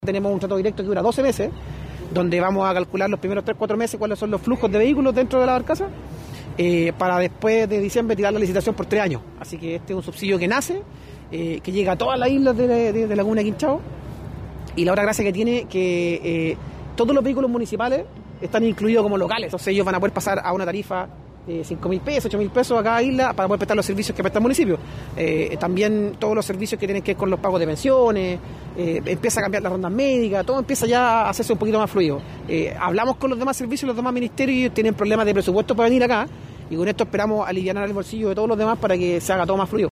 Dicho acuerdo comenzará a hacerse realidad desde los primeros días del mes de septiembre próximo, expresó el seremi del ramo, Nicolás Céspedes.